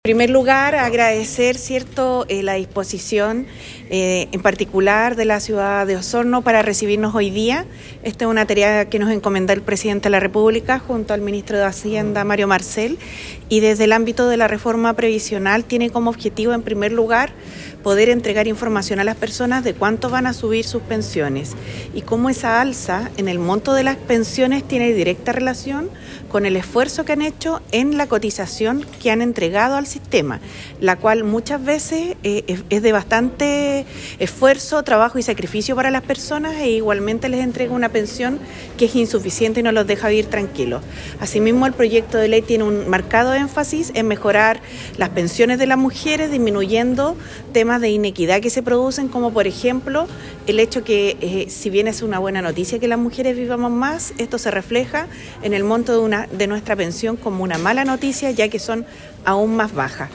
Ministros del Trabajo y Hacienda expusieron en el Teatro Municipal de Osorno
La Ministra del Trabajo y Previsión Social, Jeannette Jara indicó que el foco principal desde el área de la reforma de pensiones es dilucidar las dudas, acerca de como un cambio legislativo podría mejorar las jubilaciones.